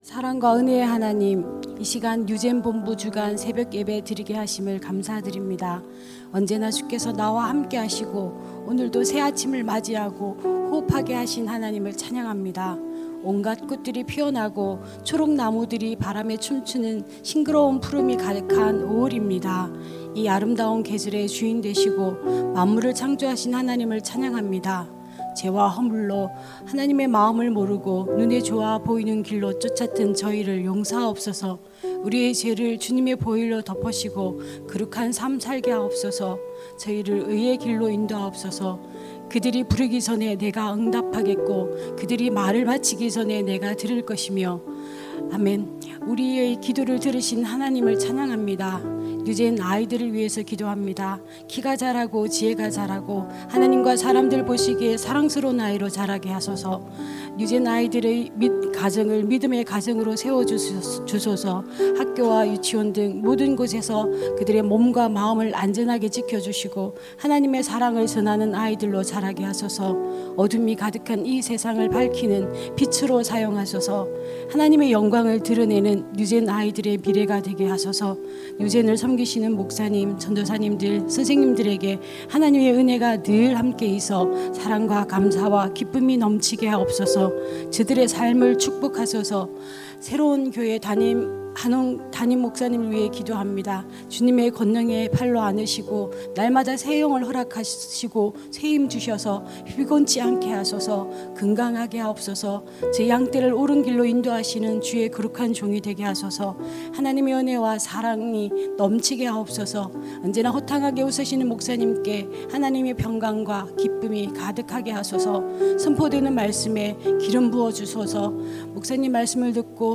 > 설교